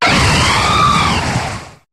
Cri de Boréas dans sa forme Totémique dans Pokémon HOME.
Cri_0641_Totémique_HOME.ogg